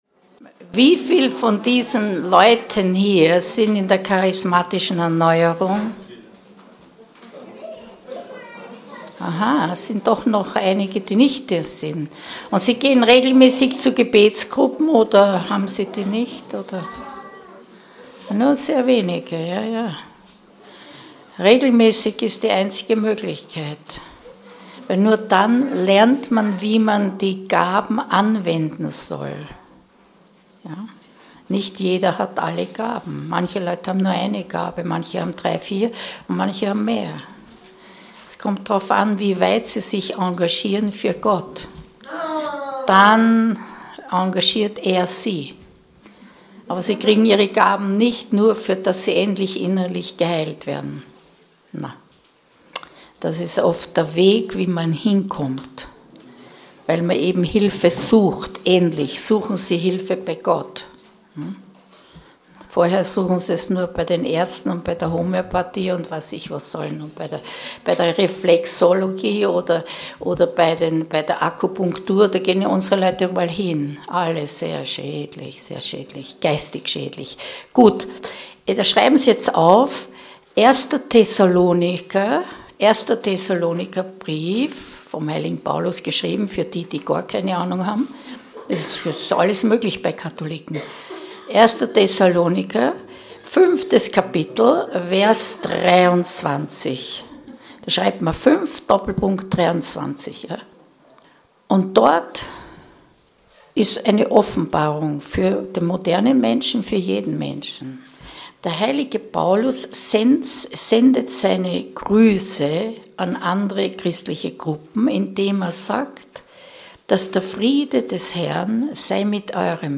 Exerzitien für Innere Heilung in Marienfried September 2014
Anm.: Während des Schreibens an der Tafel (größerer Mikrofonabstand) ist die Qualität der Aufzeichnung trotz nachträglicher Lautstärkeanpassung dementsprechend geringer.